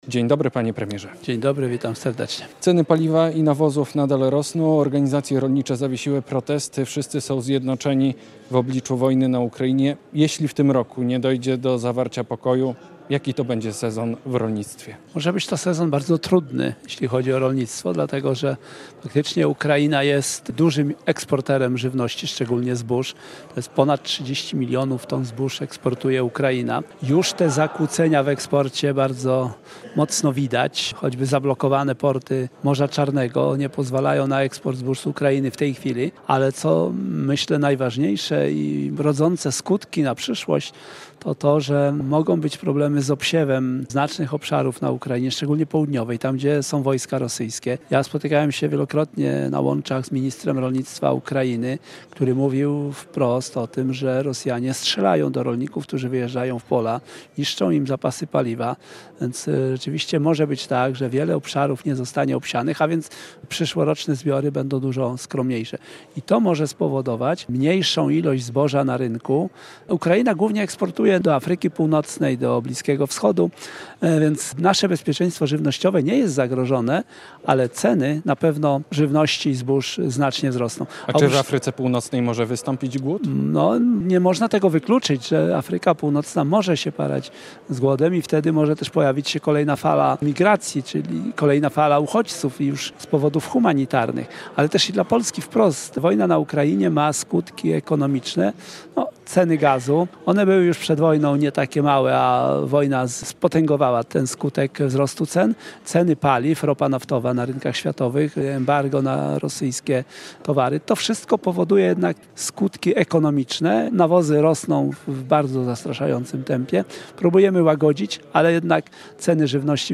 wicepremier, minister rolnictwa